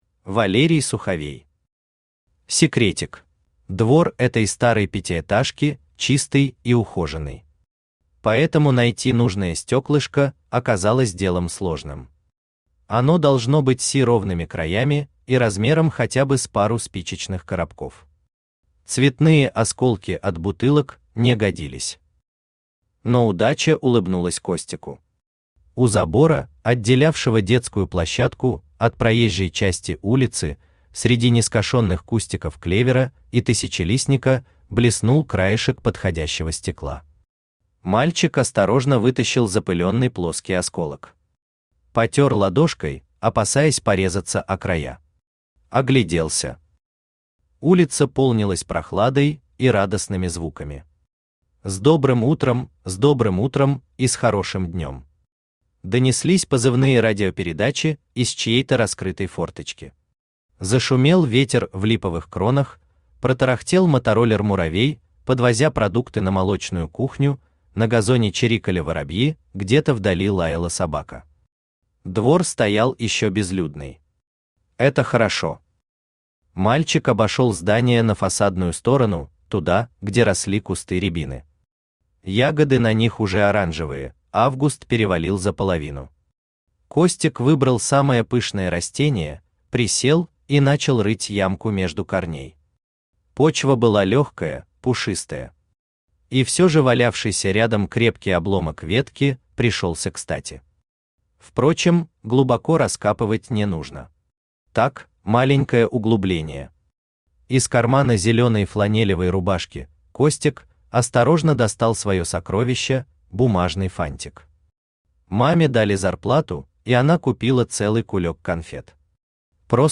Аудиокнига Секретик | Библиотека аудиокниг
Aудиокнига Секретик Автор Валерий Юрьевич Суховей Читает аудиокнигу Авточтец ЛитРес.